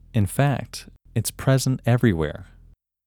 WHOLENESS English Male 18
WHOLENESS-English-Male-18.mp3